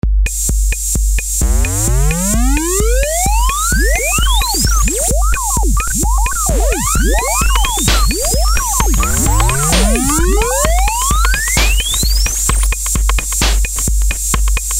> Laptopfiepen
Irgendwie klackt die Festplatte ganz komisch  :-D
Und das Fiepsen hört man auch ganz deutlich...
fiepsen.mp3